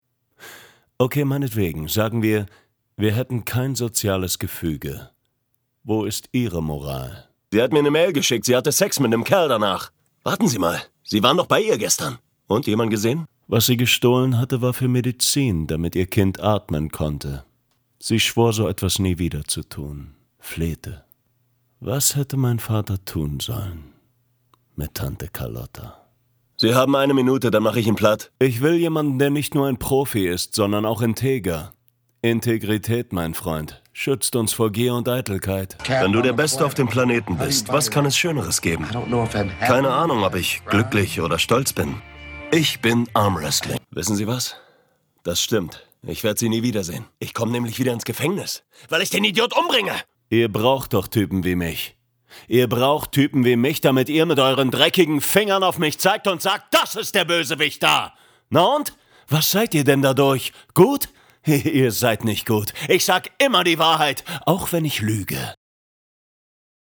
Sorry, Dein Browser unterstüzt kein HTML5 2018 - Synchron - Stimmproben 2018 - Gesang - Schlager/Rock/Country/Musical/Pop/Jazz 2020 - Hörbuch 2018 - Werbung - Coke Zero